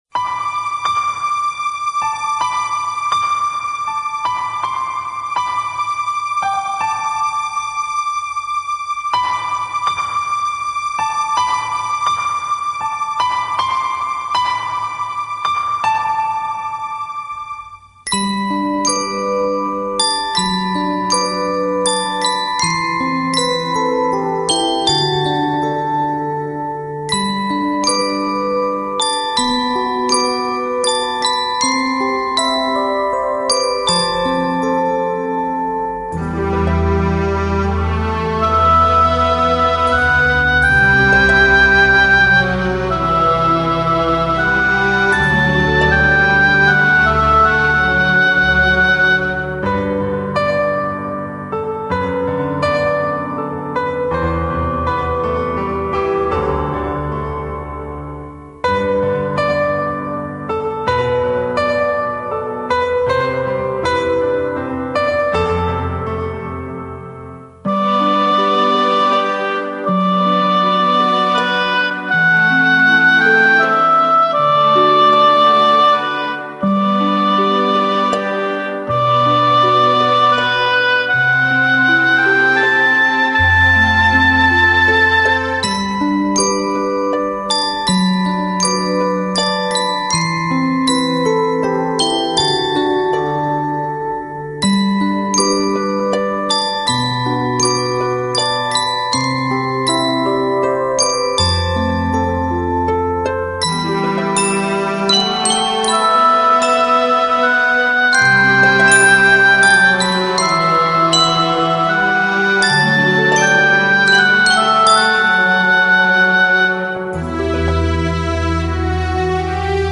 纯音乐
灵动的水晶琴音将各式经典名曲演绎得如同回响于天际间的天籁之声，
清脆、悦耳的琴似细雨滴水晶诱发出的奇妙韵律，
纯净、剔透、清脆、轻盈，映照人世的匆匆，折射都市的喧嚣，安抚虚空的心灵。
空灵、飘渺的共鸣声，烘托出万籁俱寂。
水晶琴CD1